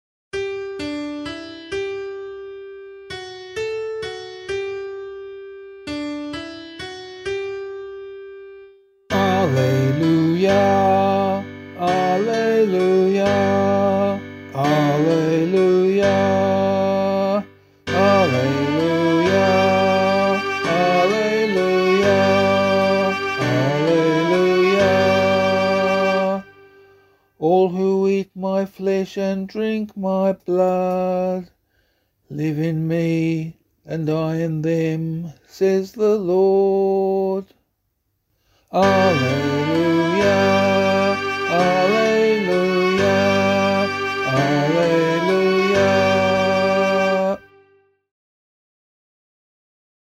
Gospel Acclamation for Australian Catholic liturgy.